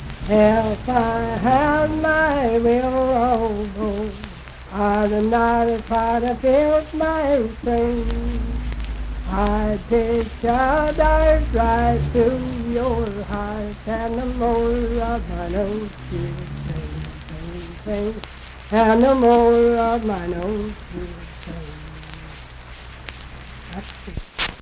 My Willow Bow - West Virginia Folk Music | WVU Libraries
Unaccompanied vocal music
Performed in Ivydale, Clay County, WV.
Voice (sung)